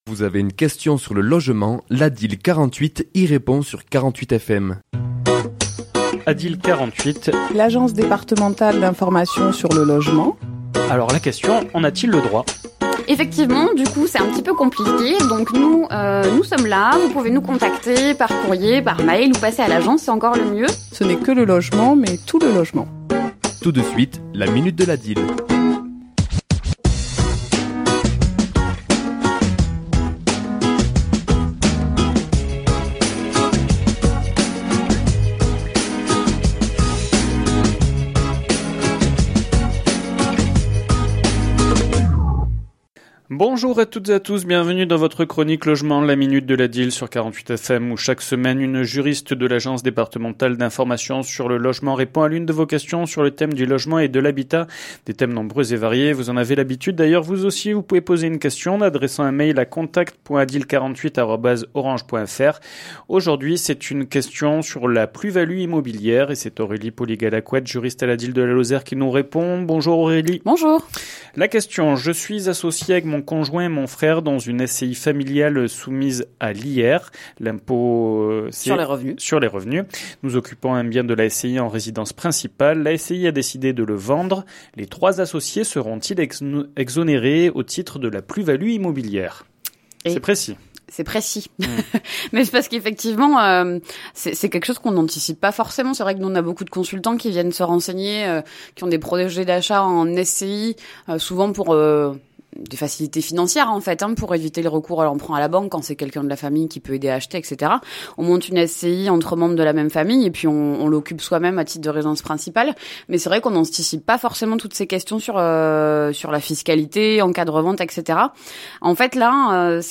Chronique diffusée le mardi 29 mars à 11h00 et 17h10